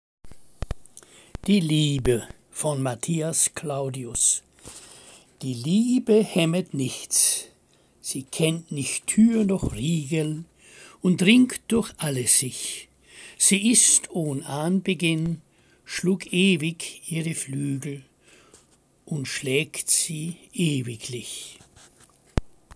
Lesung Gedichte aus der Romantik